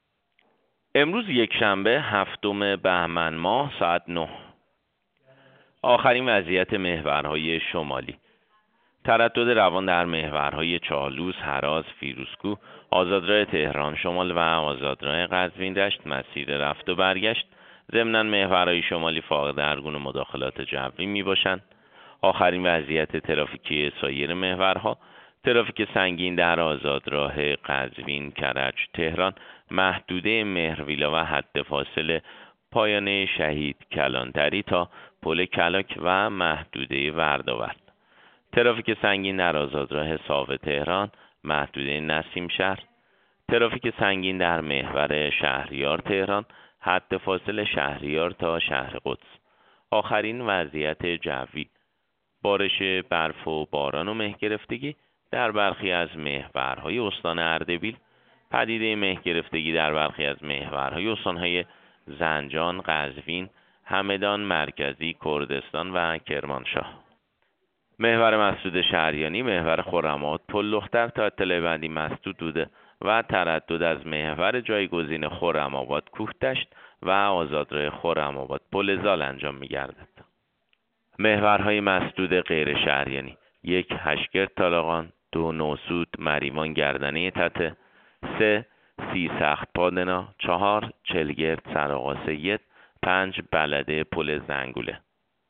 گزارش رادیو اینترنتی از آخرین وضعیت ترافیکی جاده‌ها ساعت ۹ هفتم بهمن؛